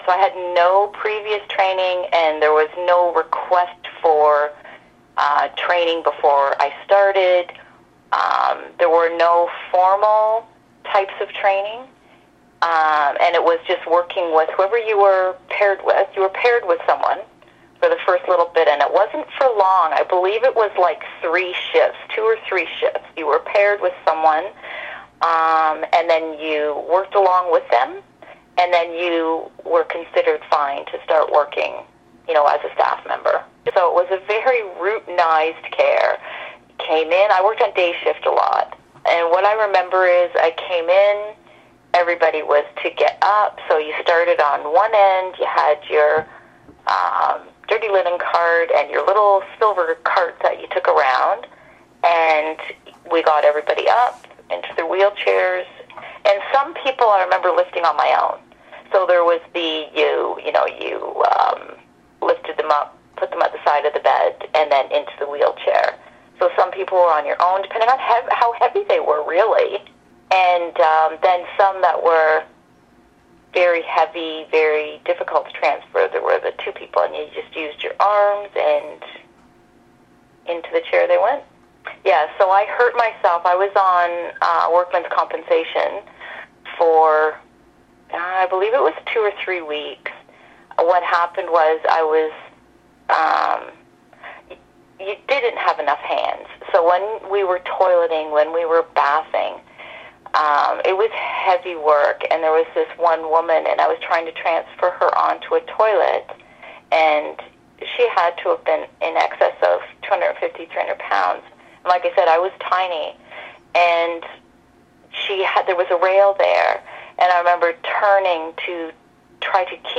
Listen to a woman who worked as a nursing attendant at Greenacres in the 1980s, describe conditions of work and care at the institution.  Compassion for the residents is evident in her narrative, but so too are the limitations to good care created by unbending institutional routines and high patient numbers.
Former-Greenacres-nusing-attendant-recalls-her-experiences.mp3